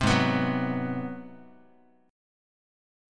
Ab7#9 chord